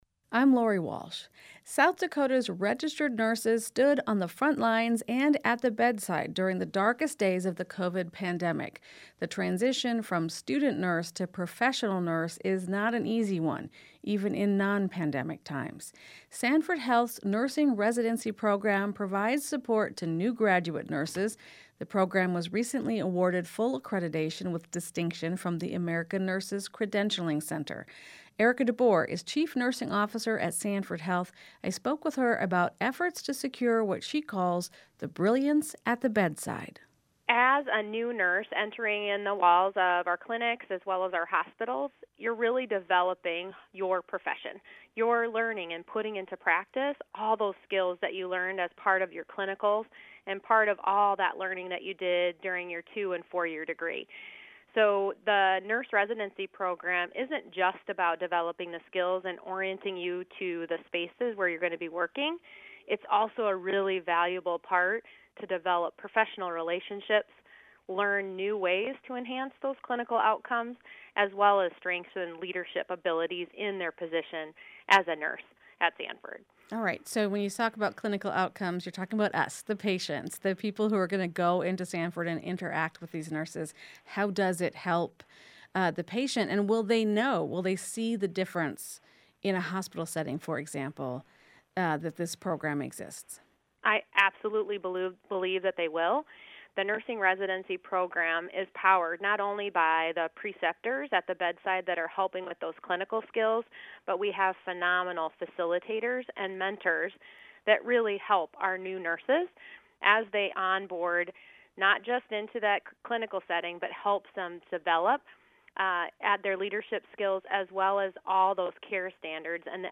This story comes from a recent interview on SDPB's weekday radio program, " In the Moment ."